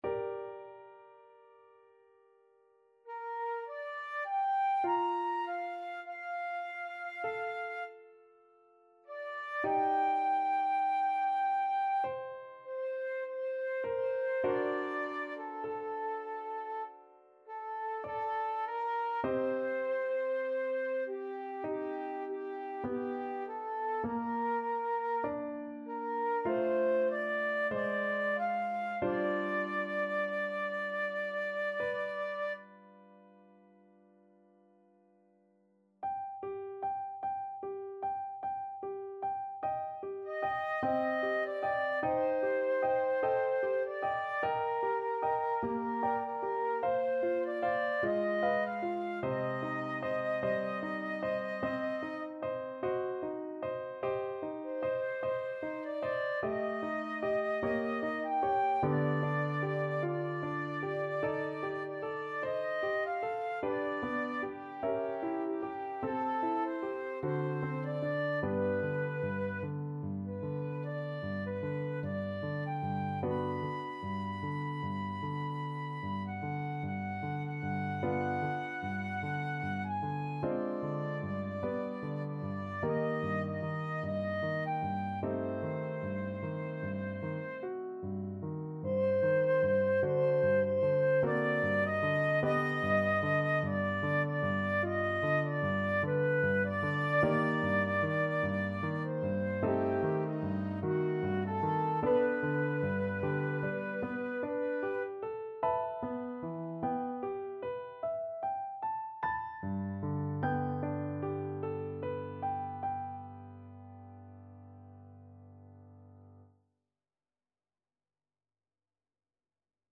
Flute
~ = 100 Lento =50
G minor (Sounding Pitch) (View more G minor Music for Flute )
Classical (View more Classical Flute Music)